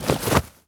foley_object_push_pull_move_04.wav